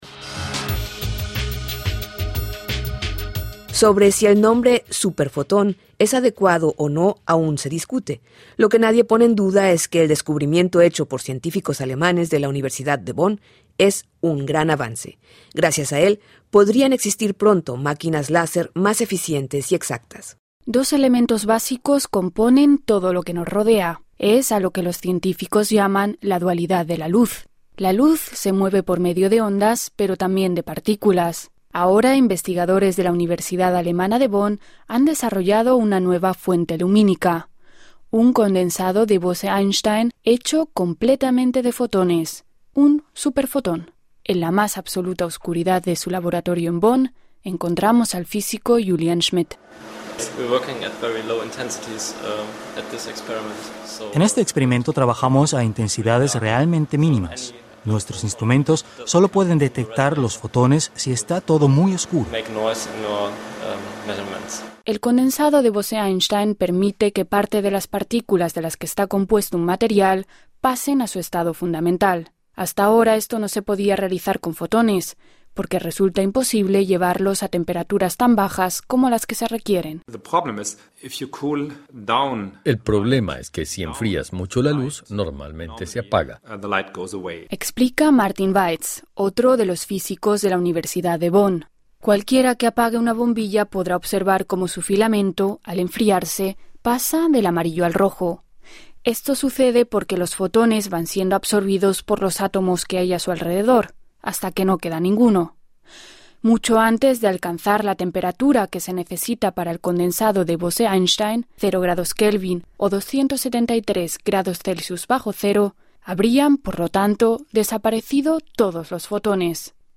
Alemania descubrió un super fotón, un avance que permitiría que existan máquinas láser más exactas. Escuche el informe de Deutsche Welle.